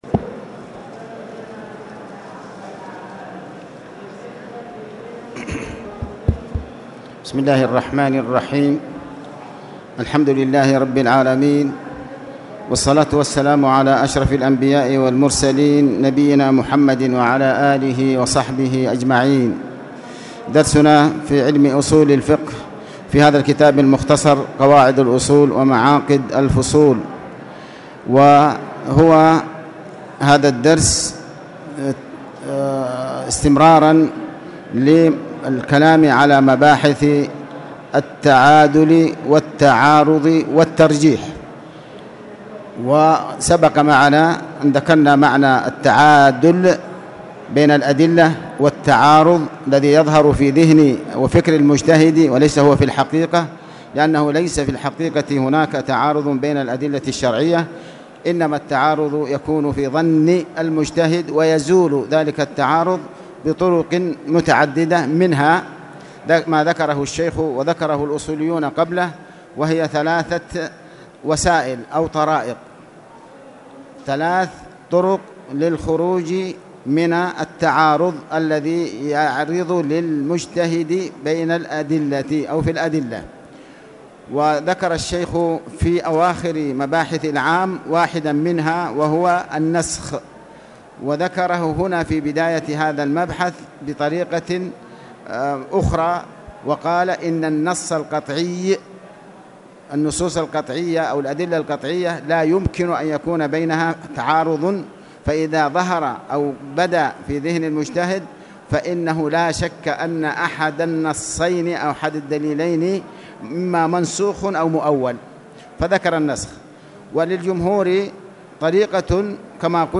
تاريخ النشر ١٥ رجب ١٤٣٨ هـ المكان: المسجد الحرام الشيخ: علي بن عباس الحكمي علي بن عباس الحكمي مباحث التعادل والتعارض والترجيح